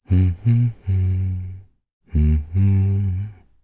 Crow-Vox_Hum_jp_c.wav